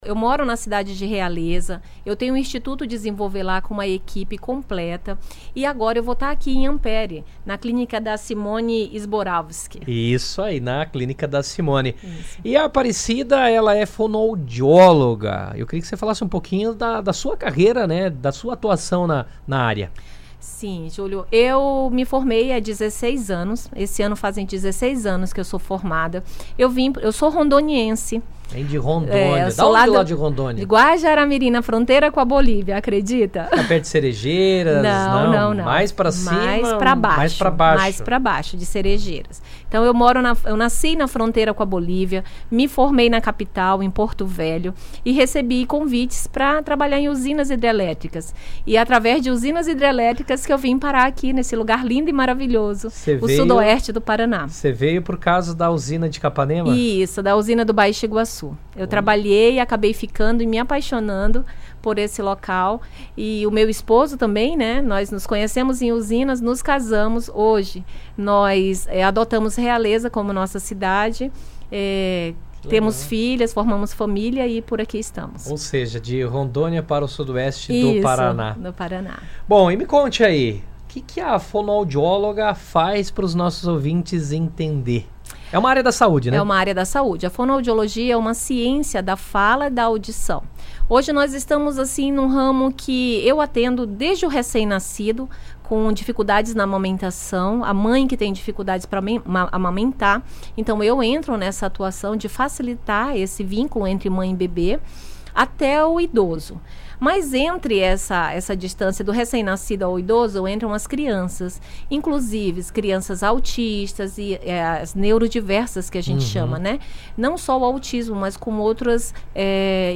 Durante a entrevista